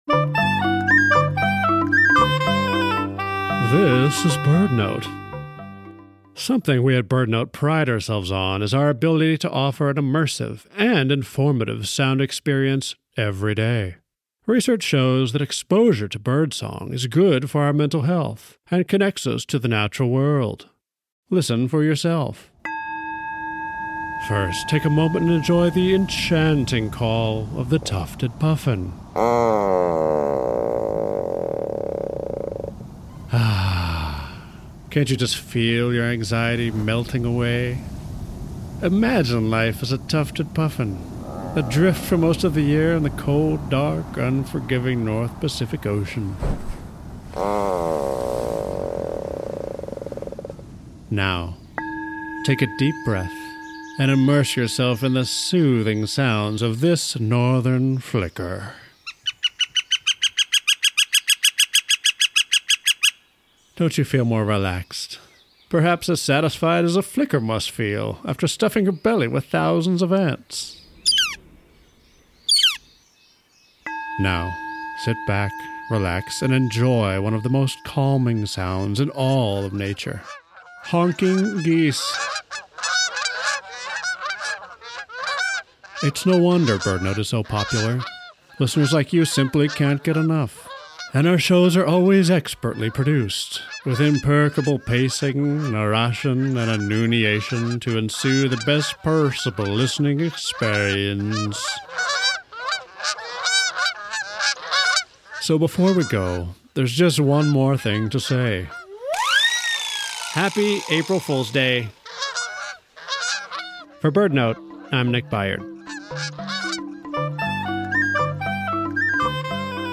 BirdNote prides itself on creating a sound-rich, immersive experience for listeners every day. Today’s episode, featuring the Tufted Puffin, the Great Horned Owl, and the Mallard is sure to bring you calm and get your day started on a gentle note.
Let BirdNote Immerse You In Soothing Birdsong